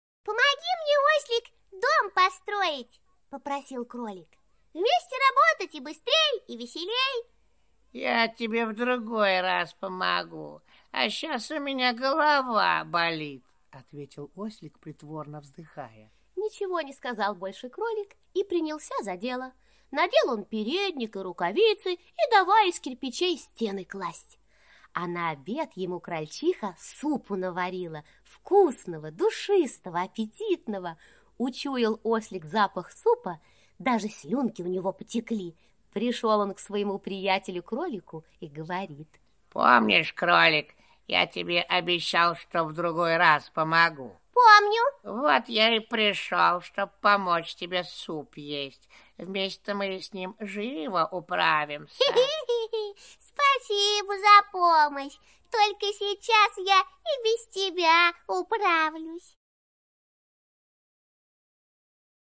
Слушайте Помощник - аудиосказка Пляцковского М.С. Сказка про то, как кролик просил ослика помочь ему дом построить, но ослику было лень.